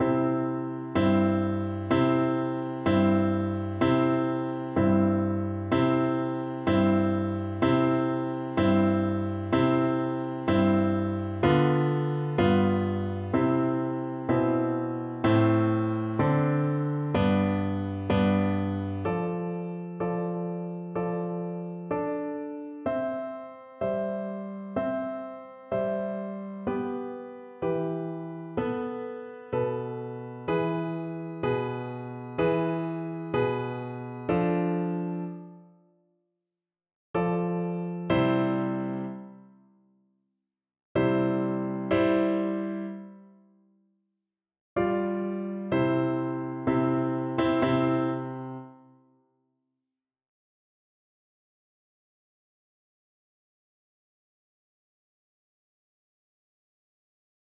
Oboe version
OboePiano
C major (Sounding Pitch) (View more C major Music for Oboe )
Maestoso = c. 100
2/4 (View more 2/4 Music)
G5-B6
Classical (View more Classical Oboe Music)